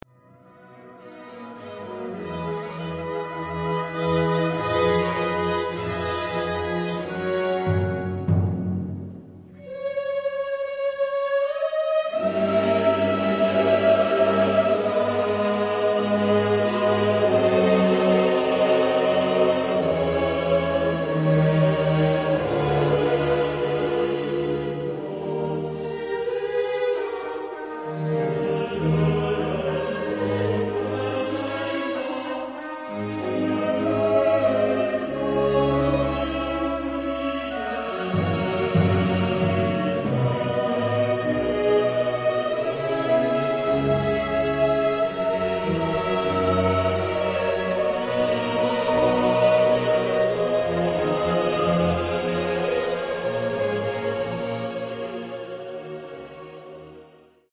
Genre-Style-Form: Baroque ; Sacred ; Mass
Type of Choir: SATB  (4 mixed voices )
Soloist(s): SATB  (4 soloist(s))
Instrumentation: Chamber orchestra  (8 instrumental part(s))
Tonality: C major